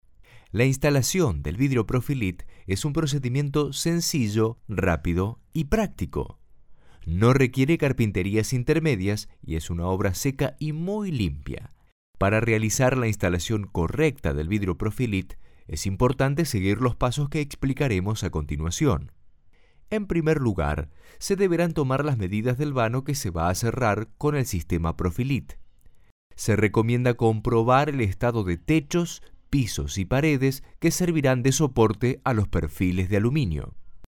spanisch Südamerika
Sprechprobe: eLearning (Muttersprache):
voice over spanish.